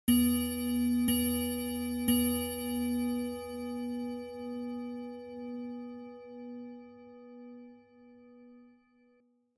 دانلود آهنگ ساعت 18 از افکت صوتی اشیاء
جلوه های صوتی
دانلود صدای ساعت 18 از ساعد نیوز با لینک مستقیم و کیفیت بالا